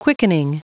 Prononciation du mot quickening en anglais (fichier audio)
Prononciation du mot : quickening